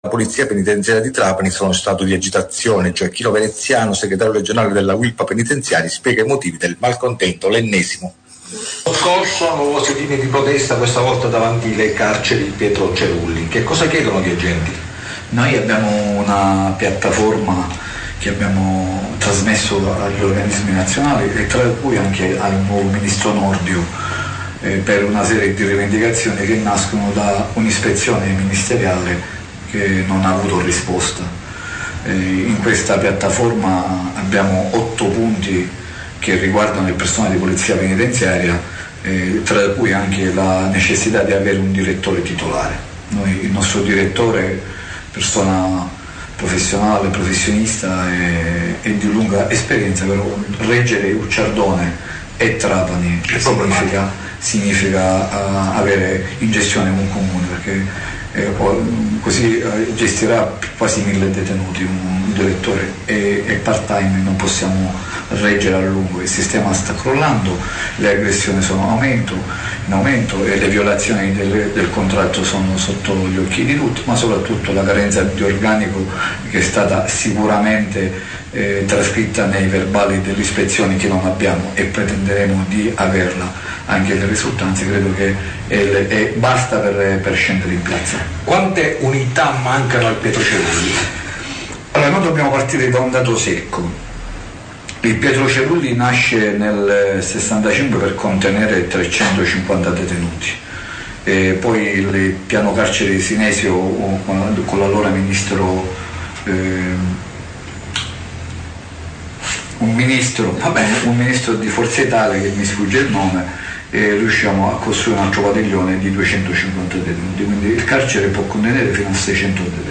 POLIZIA PENITENZIARIA TRAPANI - INTERVISTA